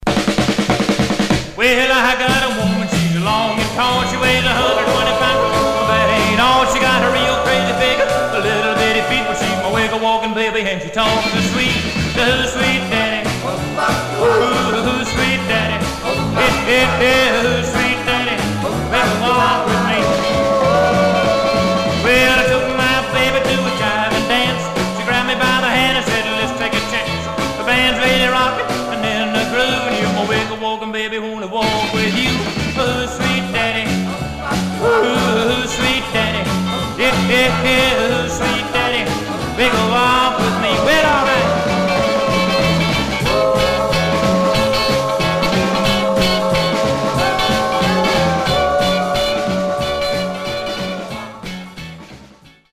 Mono
Rockabilly